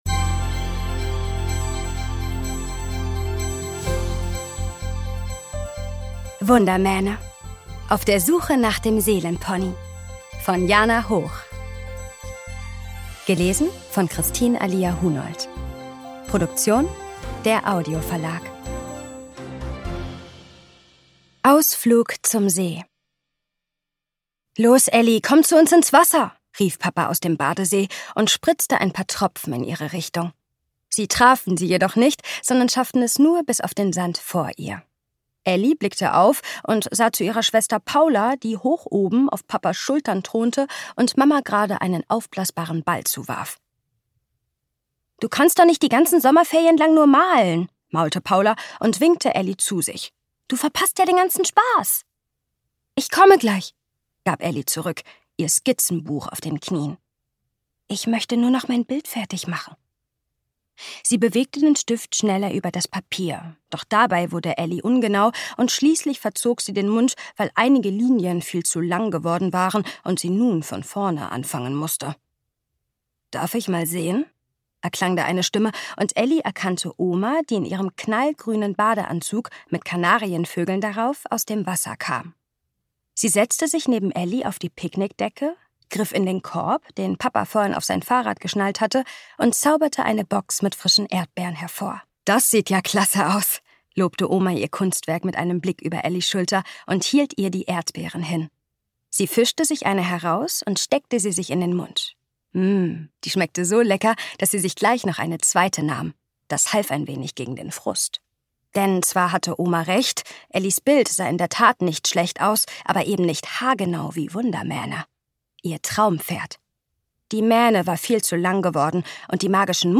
Ungekürzte Lesung mit Musik